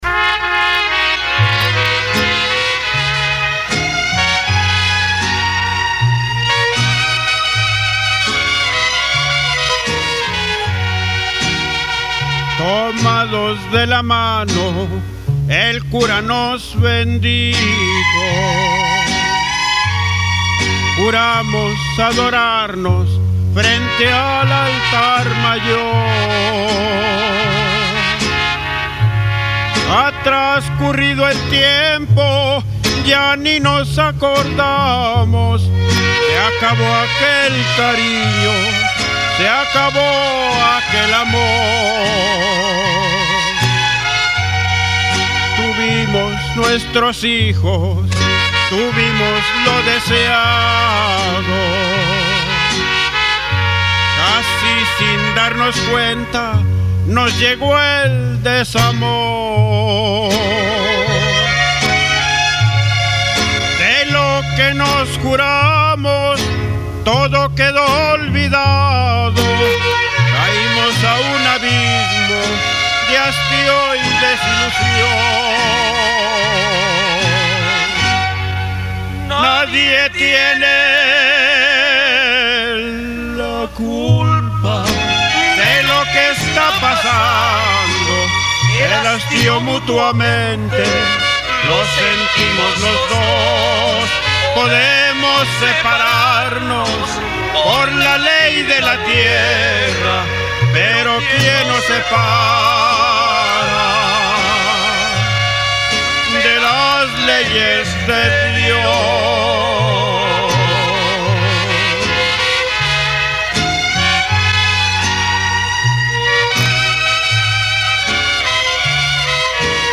musica folklorica real